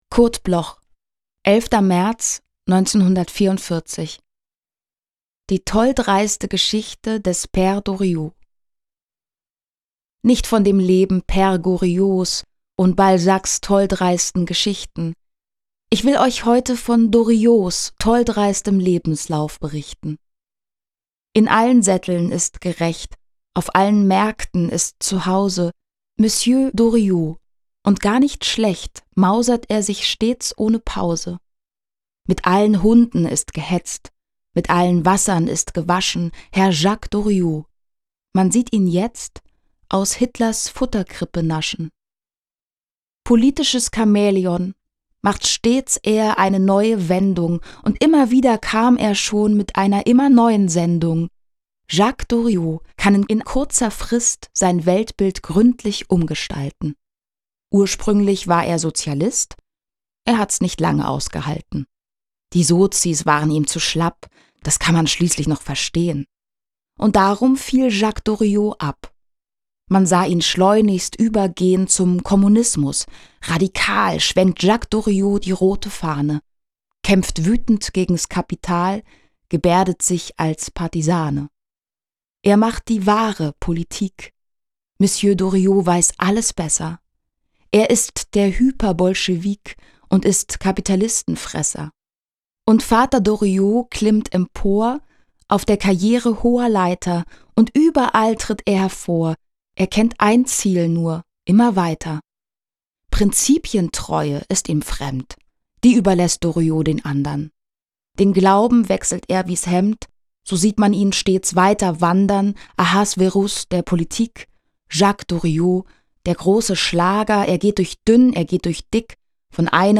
Aufnahme: Killer Wave Studio, Hamburg · Bearbeitung: Kristen & Schmidt, Wiesbaden